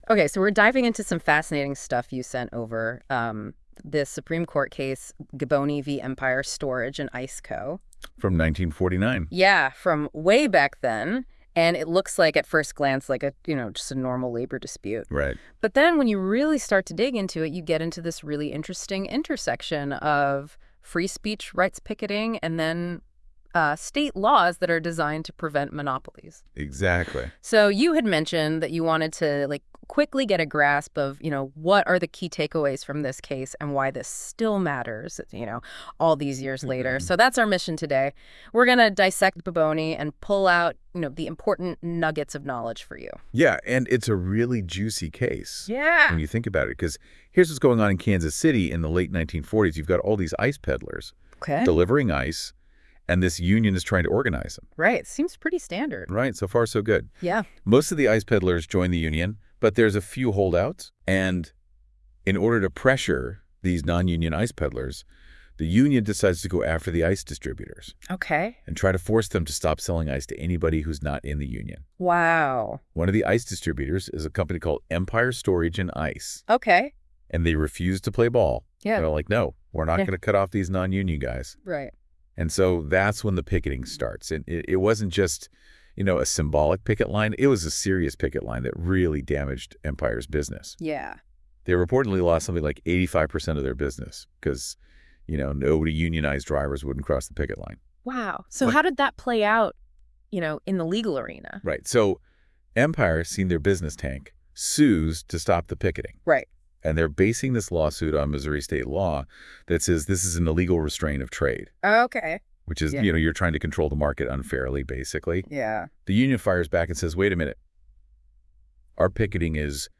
Listen to an audio breakdown of Giboney v. Empire Storage & Ice Co..